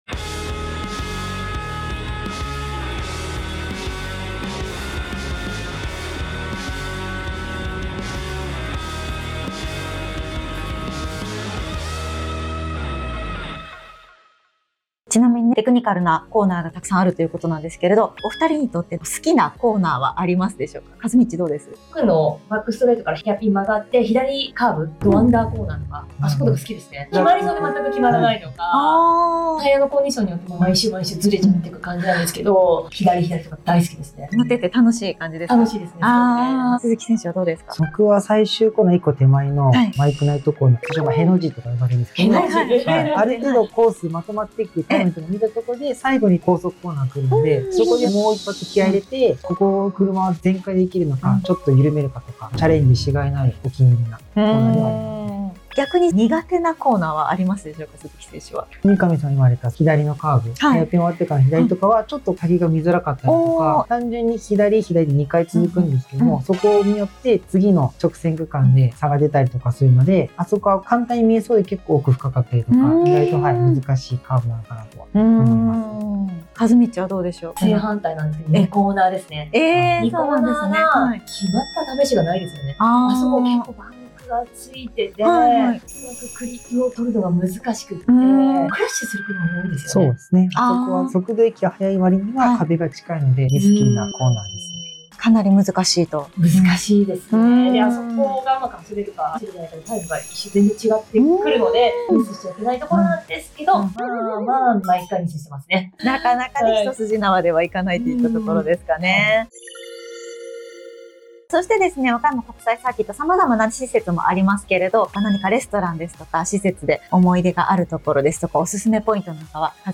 全国のモータスポーツファンへ向けての情報発信、AndRaceのポッドキャスト!